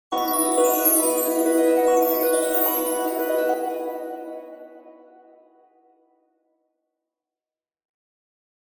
キラキラ・綺麗系_8（オーロラをイメージした綺麗な音色のキラキラ音・場面転換）